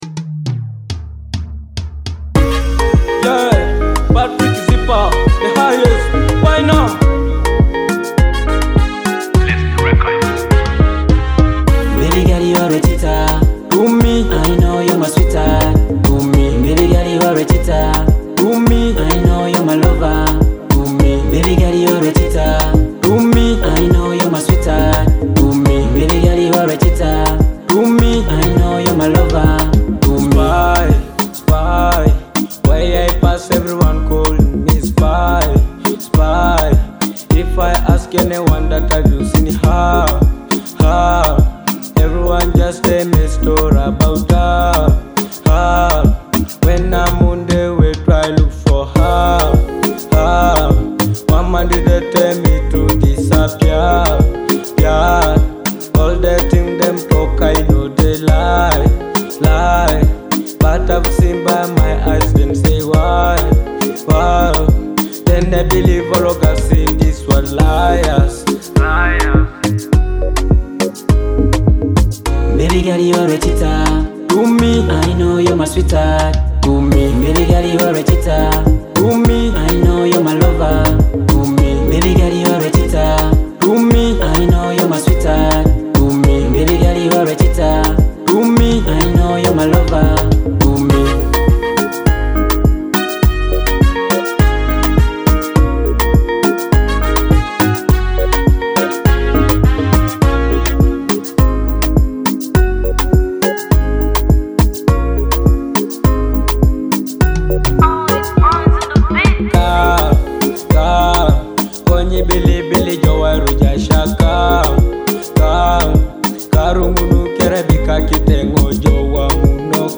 a dynamic Teso song with catchy beats and smooth rhythms.
Afrobeat